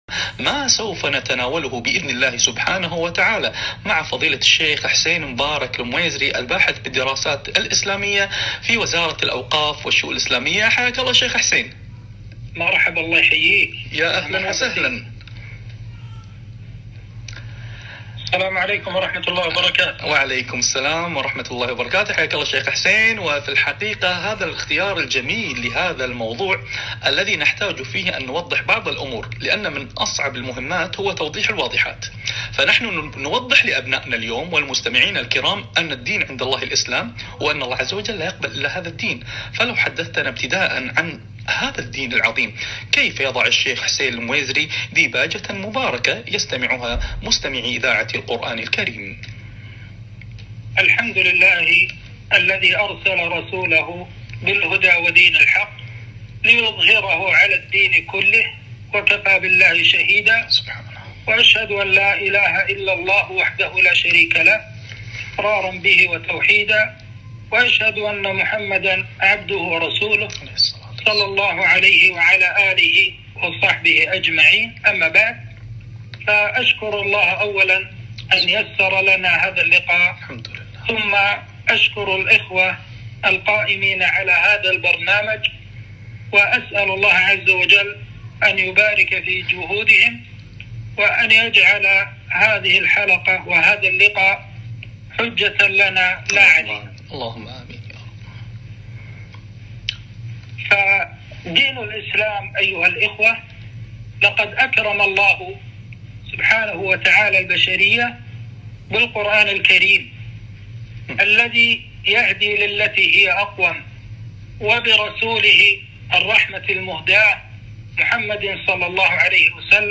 إن الدين عندالله الإسلام - لقاء إذاعي